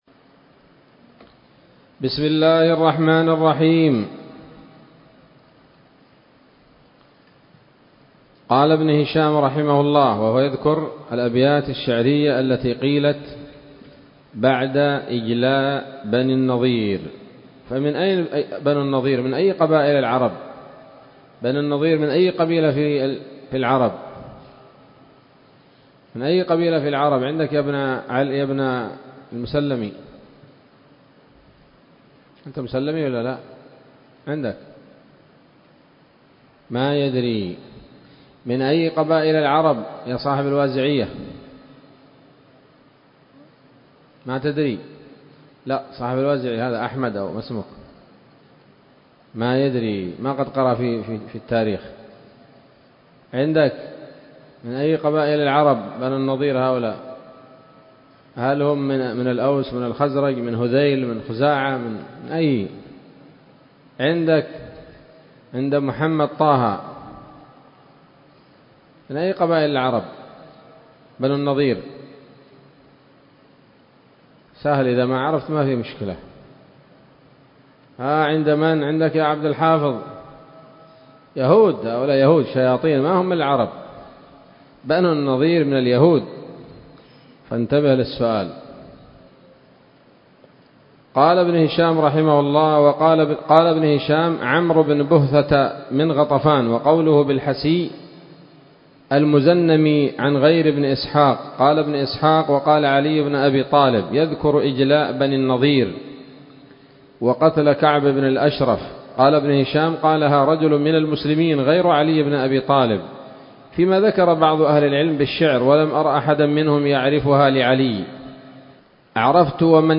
الدرس الحادي والتسعون بعد المائة من التعليق على كتاب السيرة النبوية لابن هشام